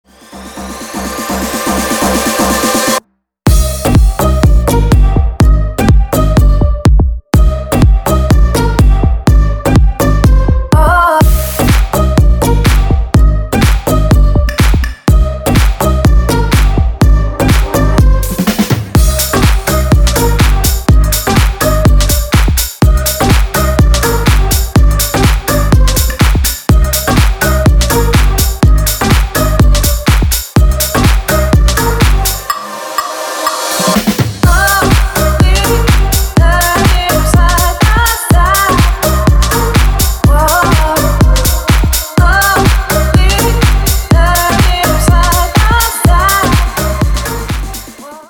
• Качество: 320, Stereo
house
клубнячок